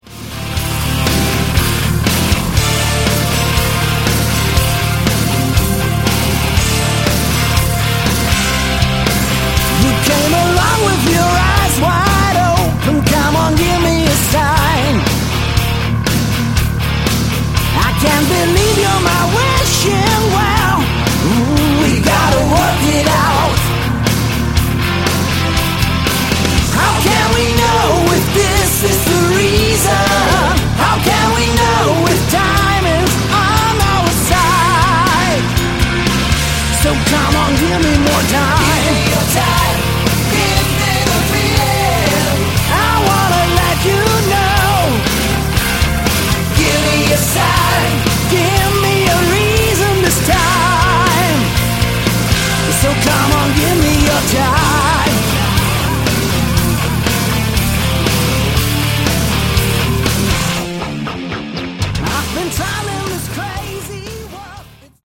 Category: Melodic Rock
Vocals
Keyboards
Sax
Guitars
Drums
Bass